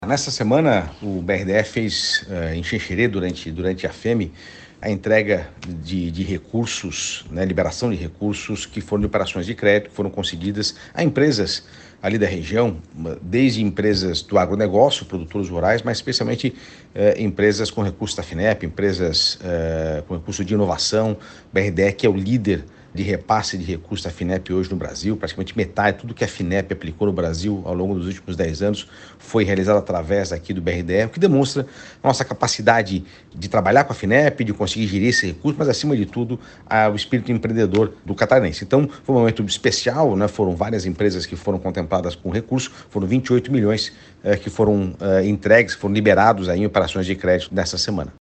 O presidente do BRDE, João Paulo Kleinübing, destacou a importância de parcerias para o desenvolvimento da região para que o recurso chegue para quem quer fazer ampliar os negócios:
SECOM-Sonora-presidente-BRDE.mp3